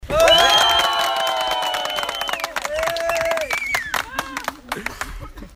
În prima sâmbătă a fiecărei luni, niște voluntari adună leu cu leu pentru prima clinică de oncologie pediatrică construită din fonduri private. La finalul alergării întregul eveniment a fost saluta de participanți prin urale …